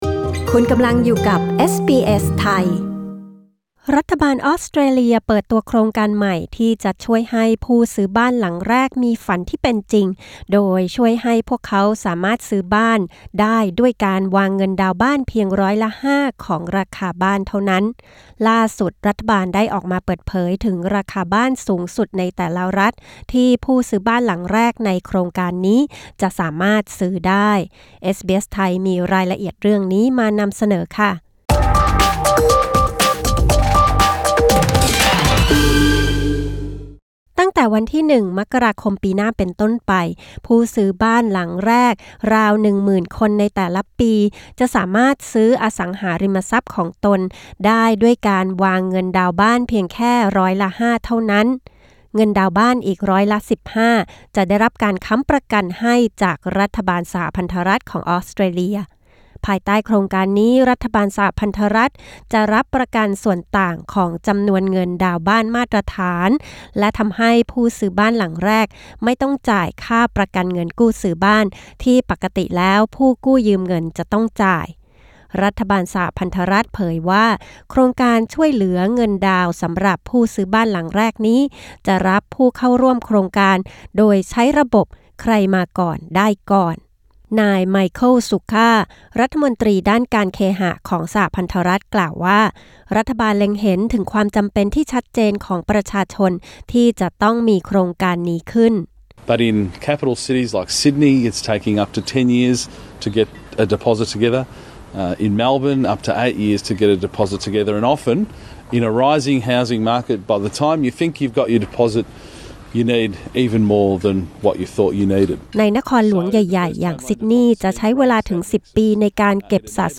NEWS: รัฐบาลออสเตรเลียเปิดตัวโครงการใหม่ที่จะช่วยให้ผู้ซื้อบ้านหลังแรกซื้อบ้านได้ด้วยเงินดาวน์แค่ร้อยละ 5 ล่าสุด รัฐบาลเผยราคาบ้านสูงสุดในแต่ละรัฐที่ผู้ร่วมโครงการจะสามารถซื้อได้